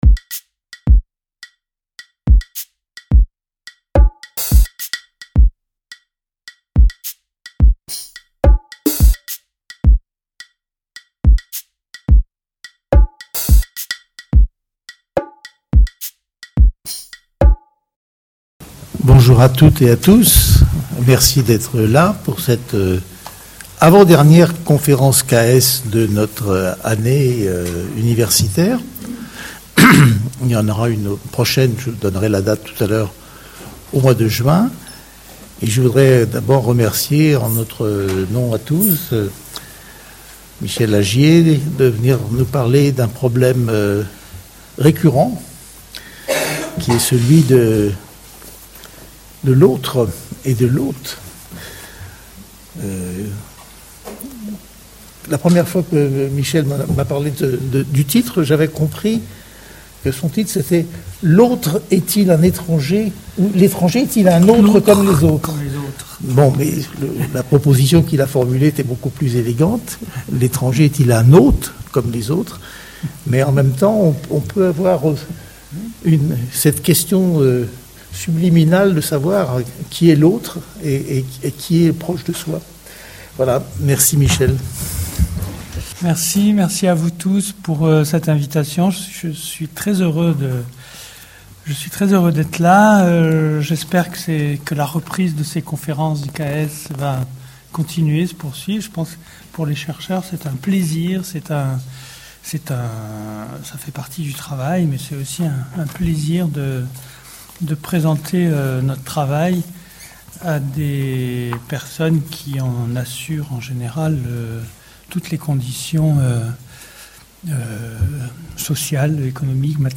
Conférence du CAES de l'EHESS